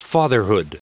Prononciation du mot fatherhood en anglais (fichier audio)